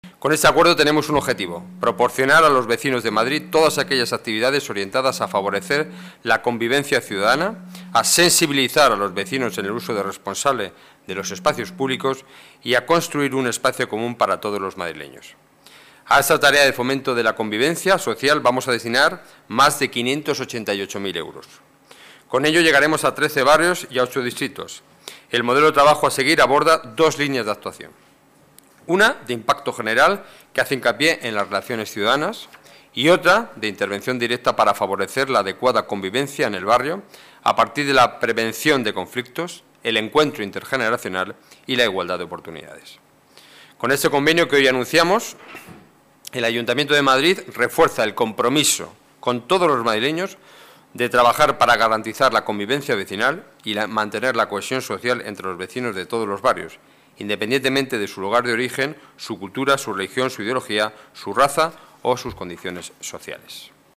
Nueva ventana:Declaraciones portavoz del Gobierno municipal, Enrique Núñez, servicio convivencia